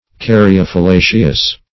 Caryophyllaceous \Car`y*o*phyl*la"ceous\, a. [Gr.